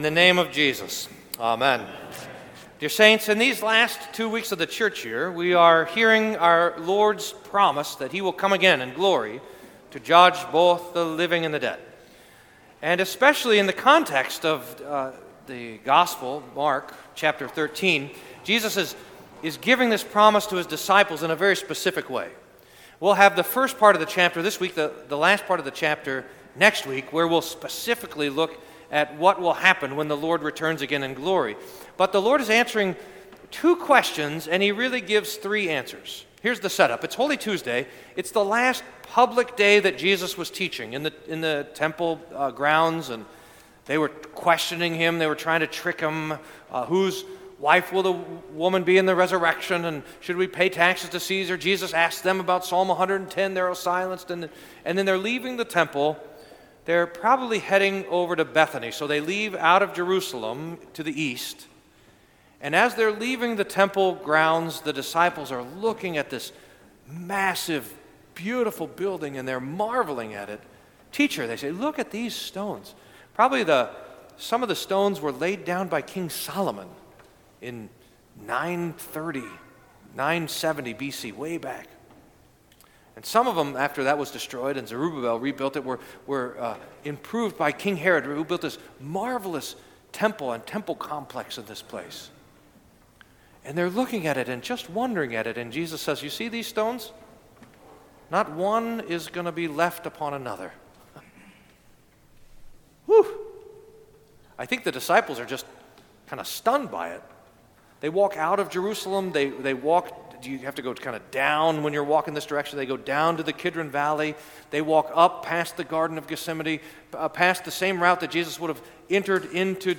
Sermon for Twenty-sixth Sunday after Pentecost